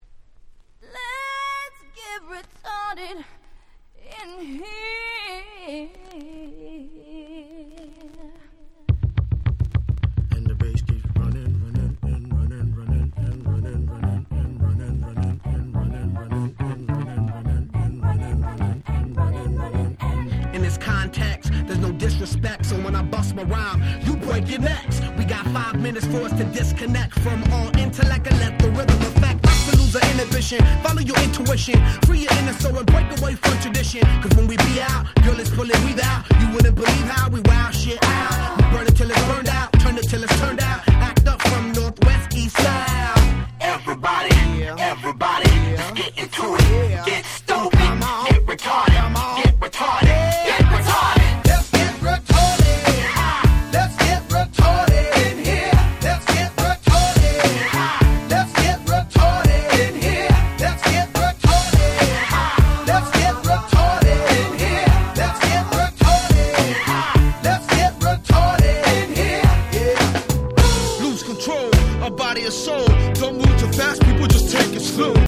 03' Super Hit Hip Hop !!
イケイケキャッチースーパーヒット！！